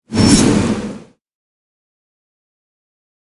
Wind Sword Slice
Wind Sword Slice is a free nature sound effect available for download in MP3 format.
Wind Sword Slice.mp3